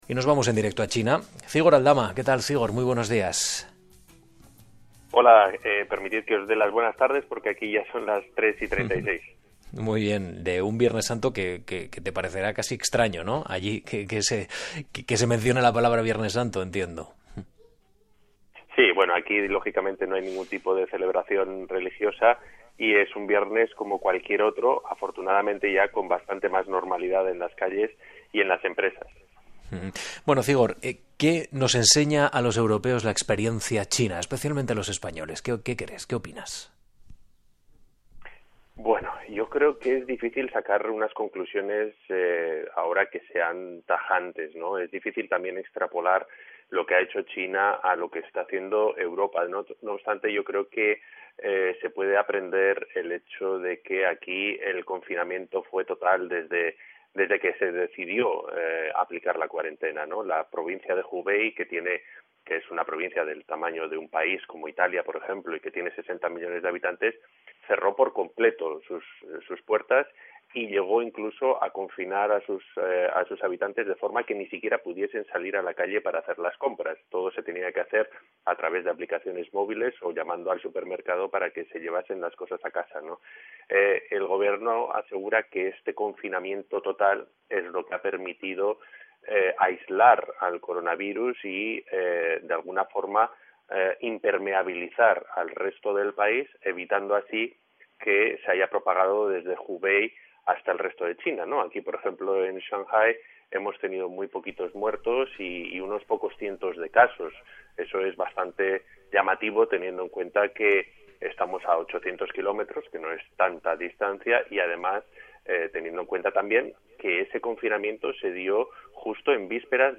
Durante la pandemia del Covid-19 en el año 2020 algunos gobiernos pusieron en marcha aplicaciones que controlan la geolocalización de los ciudadanos para evitar la transmisión del virus. Aquí te comparto una entrevista de RNE en donde se habla del conflicto de estas aplicaciones con la privacidad de la ciudadanía.